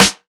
Royality free snare drum tuned to the G note. Loudest frequency: 1410Hz
• Focused Snare Drum Sound G Key 437.wav
focused-snare-drum-sound-g-key-437-NeT.wav